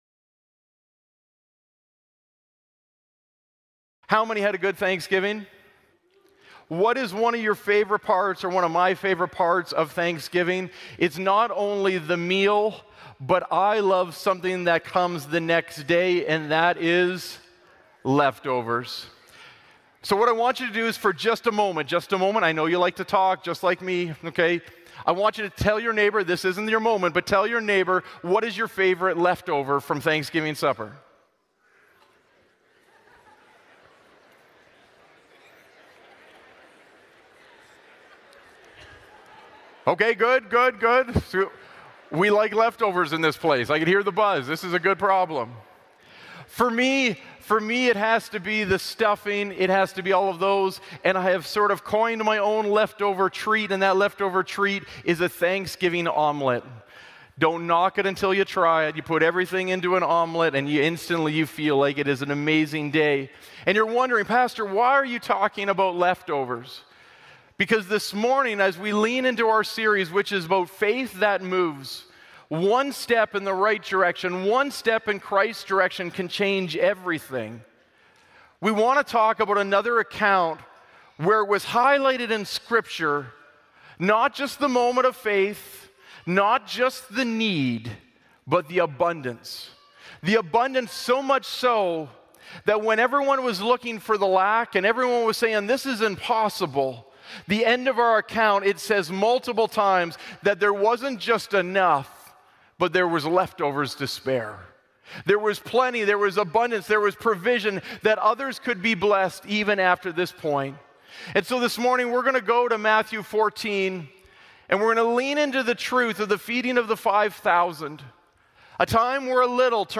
Glad Tidings Church (Sudbury) - Sermon Podcast Faith Leftovers Play Episode Pause Episode Mute/Unmute Episode Rewind 10 Seconds 1x Fast Forward 30 seconds 00:00 / 38:24 Subscribe Share RSS Feed Share Link Embed